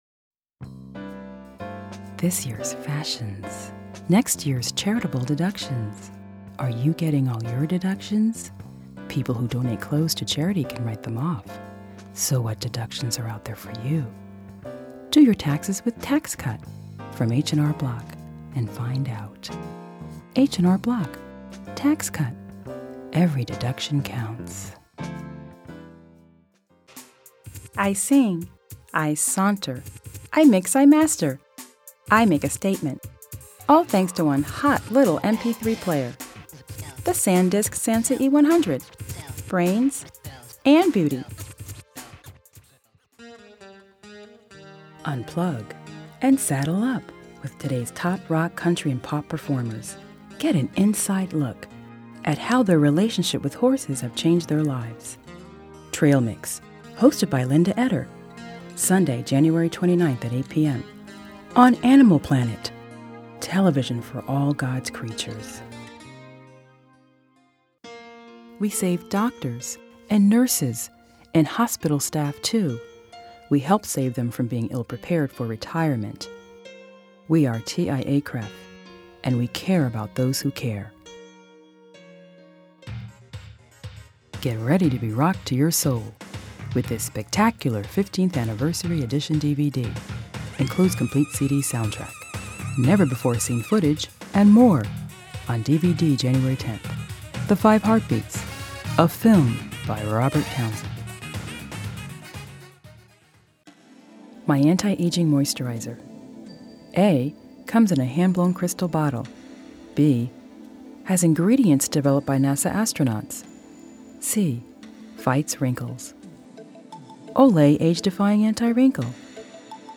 Voice overs of various commercial products.